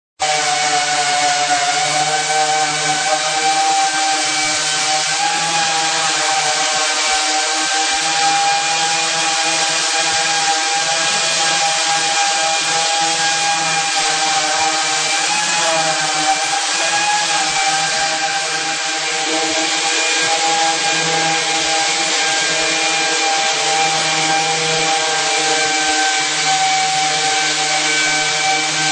2 Pulidora
Tags: martillo